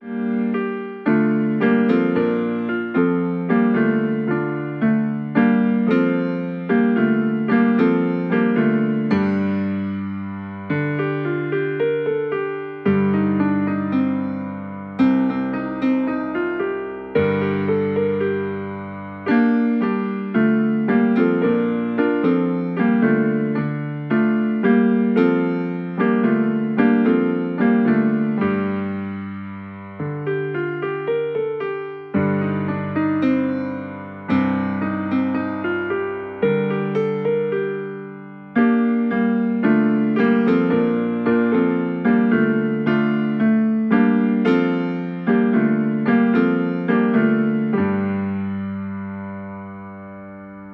interprétés dans une atmosphère chaleureuse et raffinée.